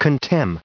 added pronounciation and merriam webster audio
1850_contemn.ogg